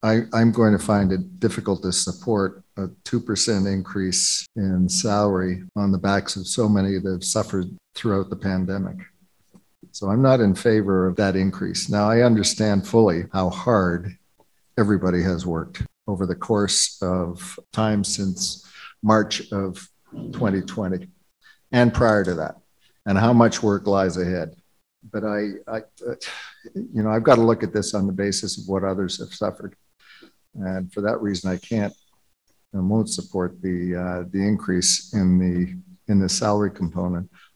Mayor Steve Ferguson opened discussion to explain why he couldn’t support the increase.